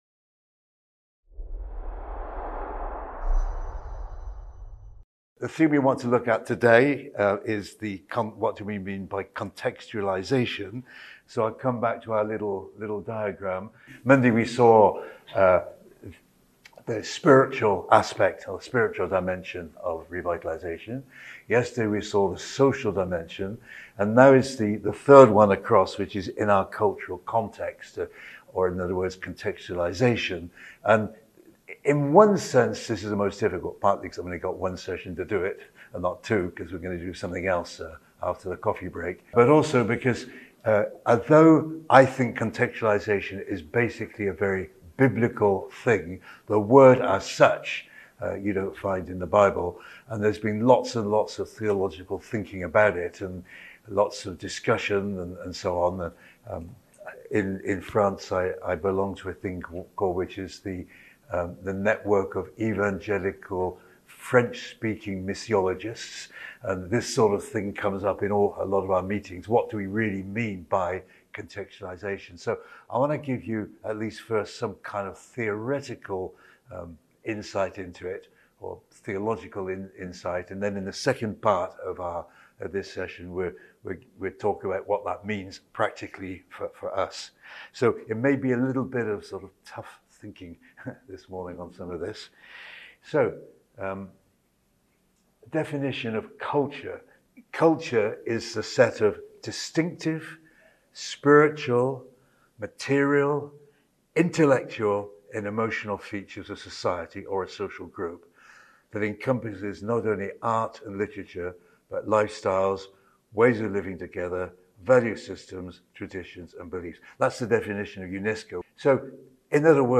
Event: ELF Church Revitalisation Network